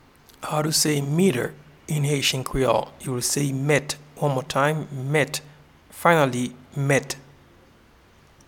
Pronunciation:
Meter-in-Haitian-Creole-Met.mp3